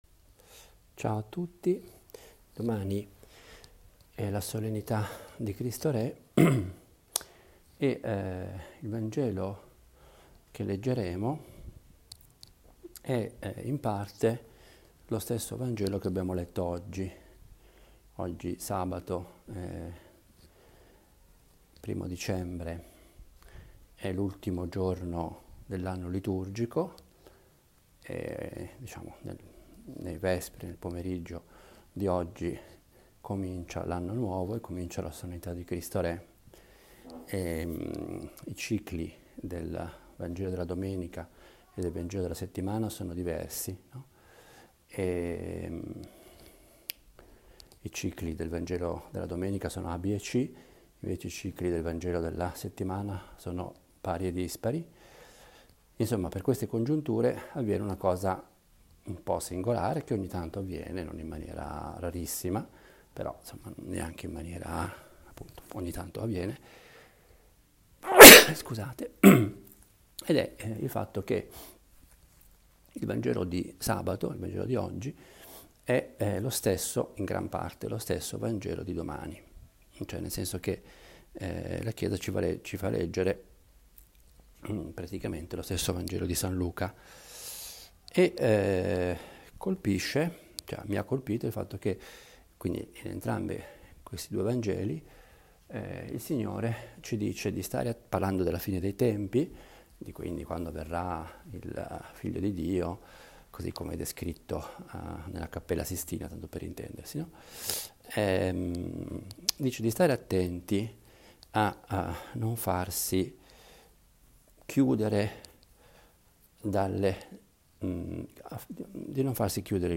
Pausa caffè a Nazareth è una riflessione breve, di otto minuti, sul vangelo della domenica. Una meditazione nella quale cerco di collegare il vangelo con la vita quotidiana e con la nostra prosa più normale: la frase di un giornale, le parole di una canzone. Vorrei avesse il carattere piano, proprio di una conversazione familiare.